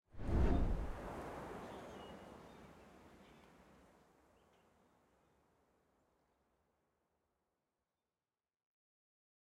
sfx_ui_map_vfx_landing.ogg